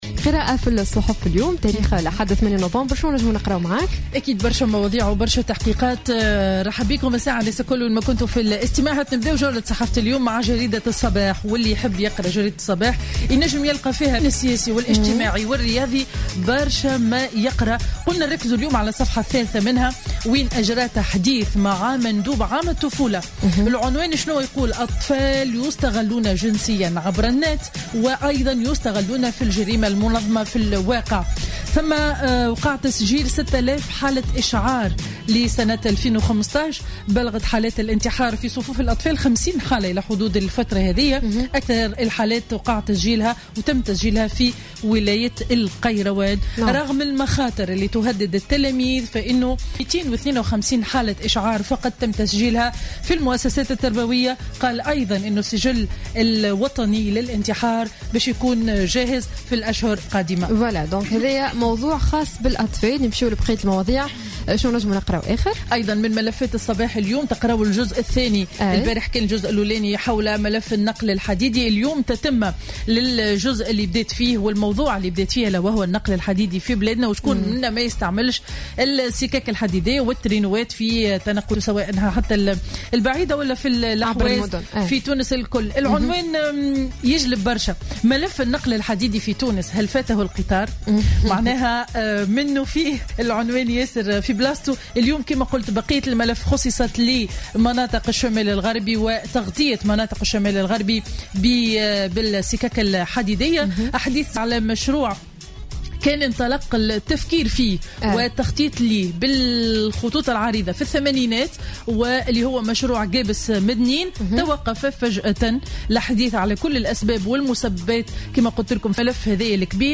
Revue de presse du Dimanche 08 Novembre 2015